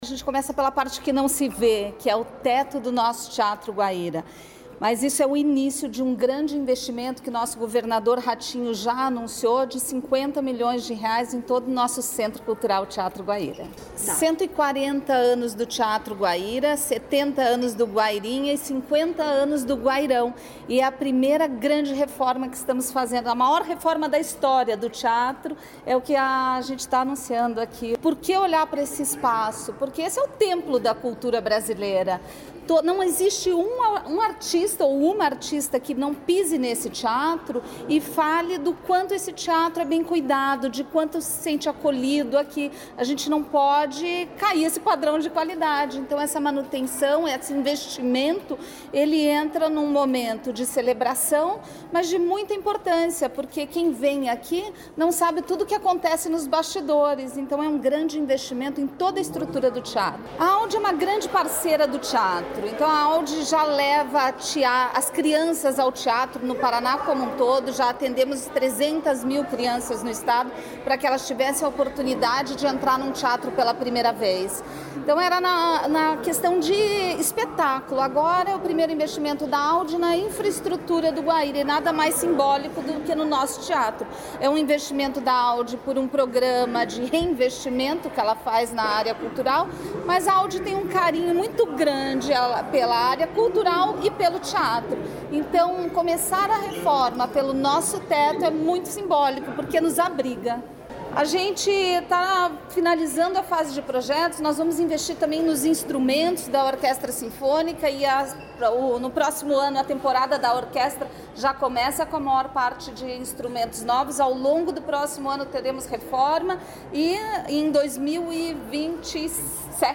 Sonora da secretária estadual da Cultura, Luciana Casagrande Pereira, sobre a reforma do teto do Teatro Guaíra